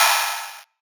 OPEN HAT 2.wav